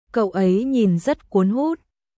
Cậu ấy nhìn rất cuốn hút彼はとても魅力的だねカウ・エイ・ニン・ザッ・クオンフット🔊